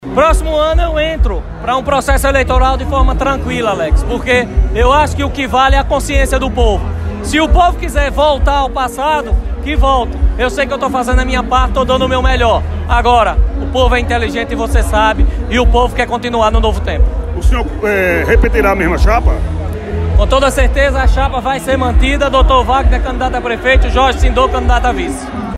Em entrevista concedida ao Jornal da Capital 1º edição, o prefeito de Moita Bonita, Dr. Vagner (PSB), confirmou com exclusividade,…
Prefeito-Vagner-Moita-Bonita.wav